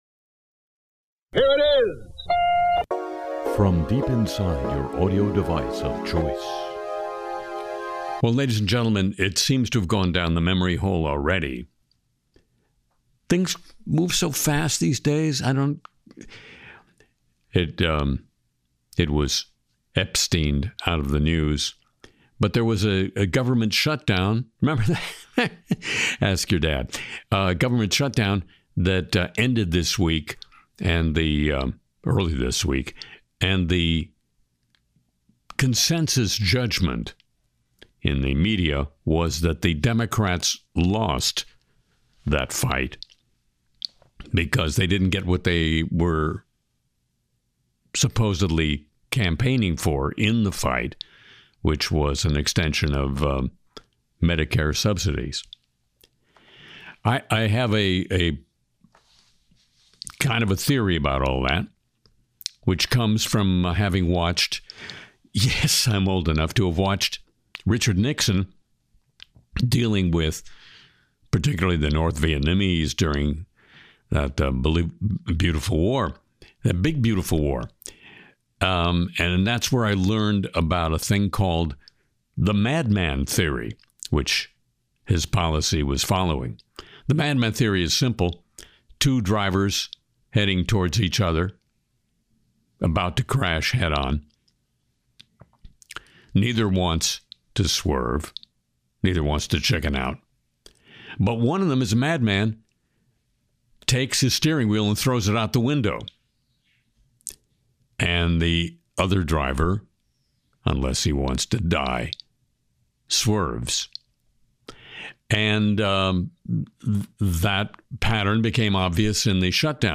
Harry Shearer sings “Autumn in New Orleans,” pitches fake AI sports bets in “Bet Plug,” mocks Trump’s Hoaxocrats, and skewers AI toys, Tinder scans, and hacking apps.